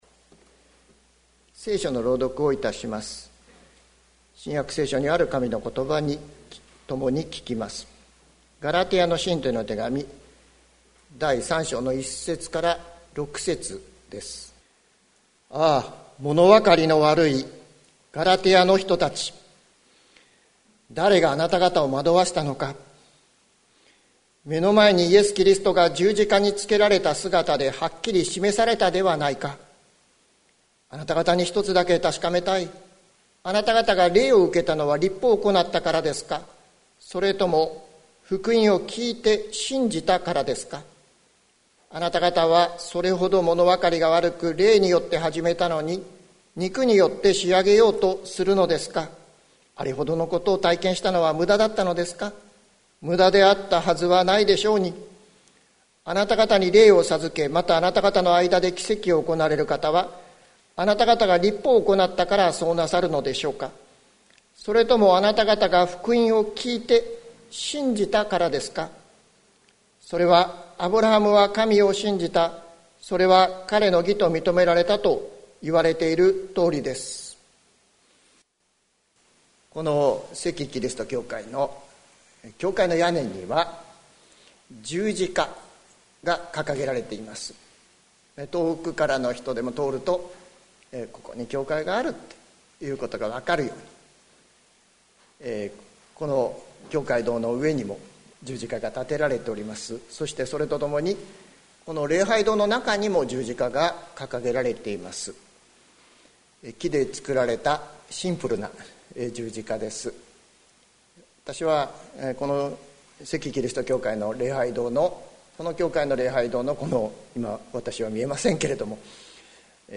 2021年05月26日朝の礼拝「十字架につけられたままのキリスト」関キリスト教会
説教アーカイブ。